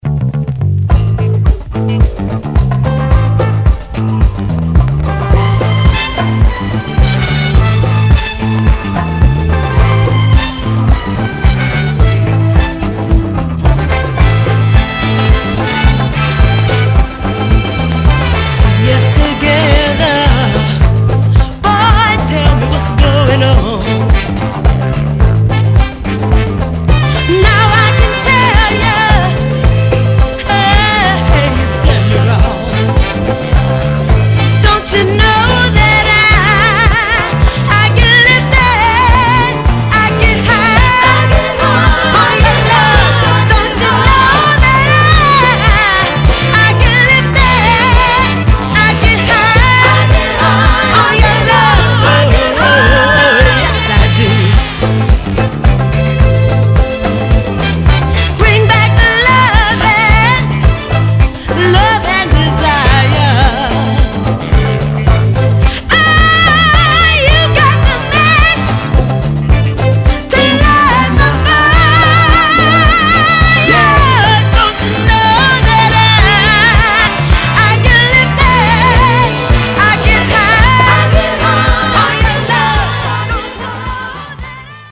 ・ 45's SOUL / FUNK / DISCO / JAZZ / ROCK
Format: 7 Inch